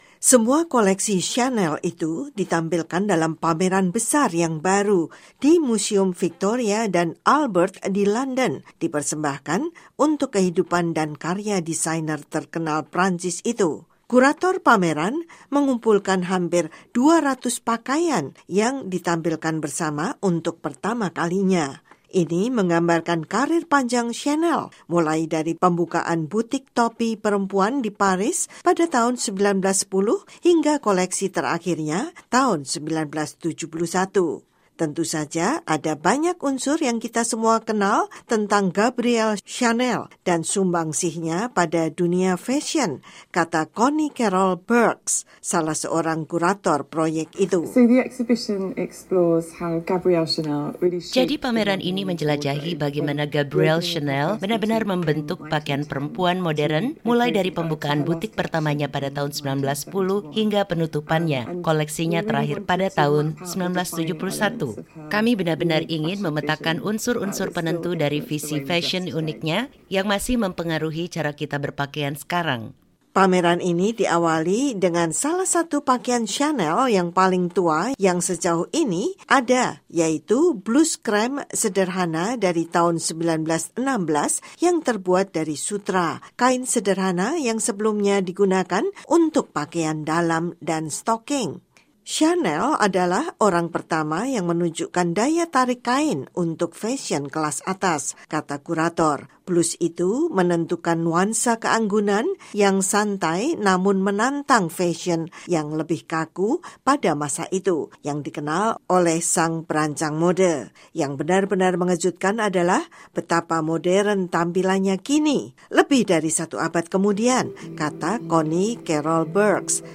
Parfum yang dikenal dengan Chanel No.5. Itu semua adalah mode klasik yang mudah dikenal, namun banyak lagi desain karya Gabrielle “Coco” Chanel yang kurang dikenal. Laporan dari kantor berita Associated Press